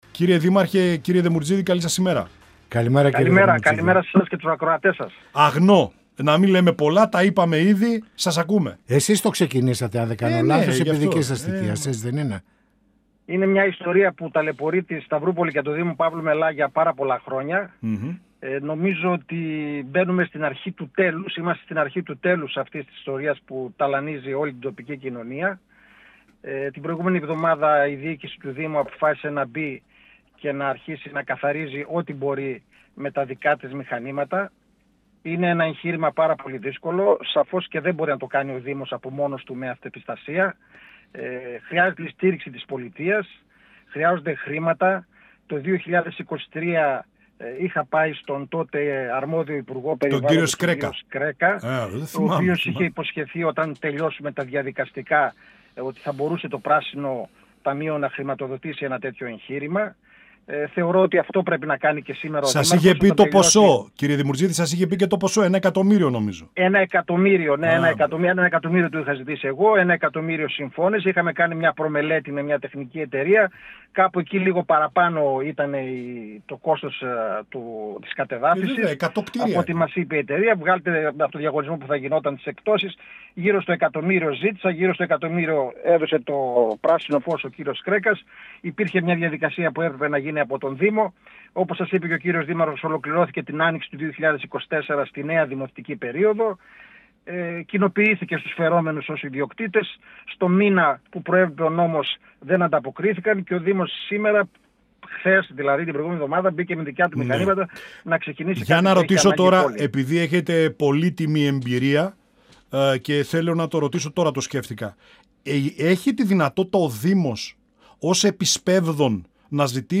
Στις εργασίες κατεδάφισης του πρώην εργοστασίου της γαλακτοβιομηχανίας ΑΓΝΟ, που εγκαταλείφθηκε πριν από 33 χρόνια και βρίσκεται στα όρια του Δήμου Παύλου Μελά, αναφέρθηκε ο π. Δήμαρχος Παύλου Μελά Δημήτρης Δεμουρτζίδης, μιλώντας στην εκπομπή «Πανόραμα Επικαιρότητας» του 102FM της ΕΡΤ3.